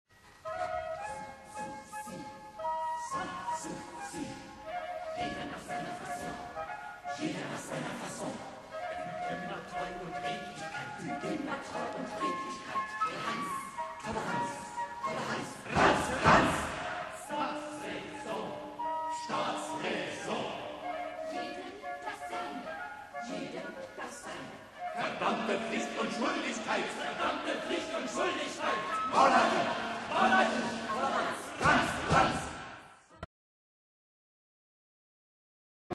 Solo-Drummer und Erzähler